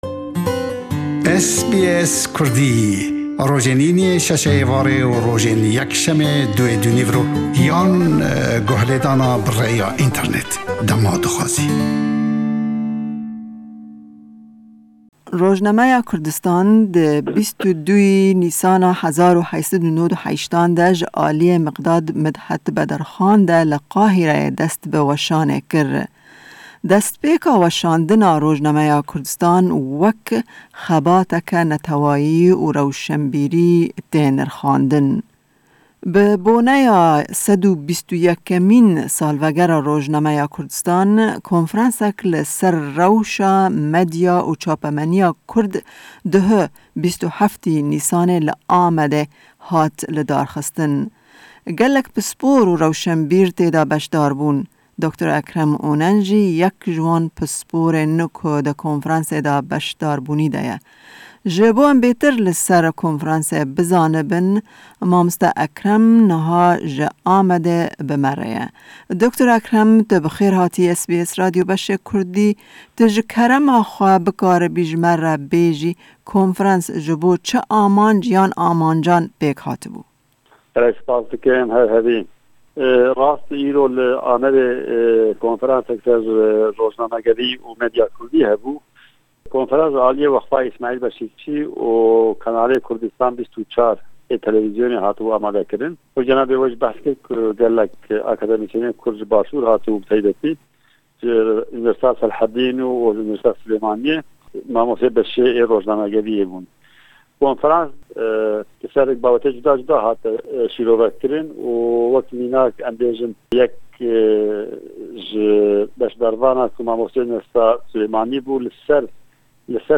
hevpeyvînek